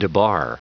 Prononciation du mot debar en anglais (fichier audio)
Prononciation du mot : debar